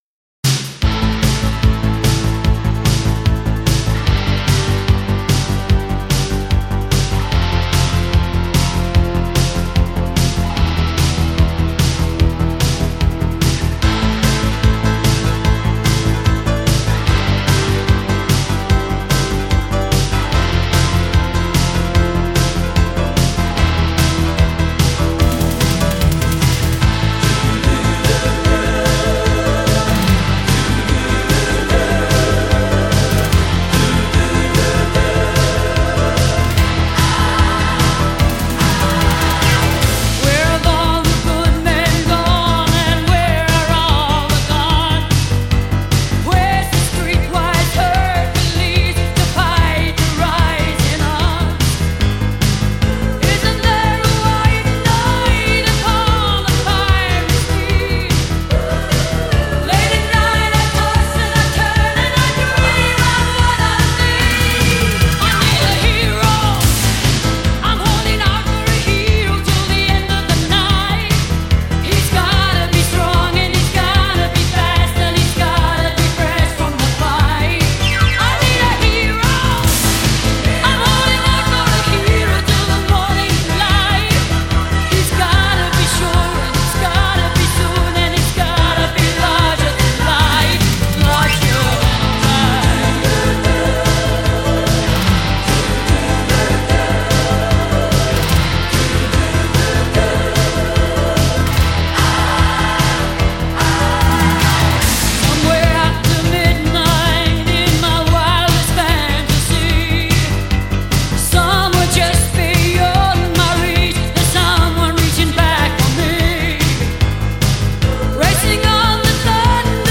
Жанр: Classic Rock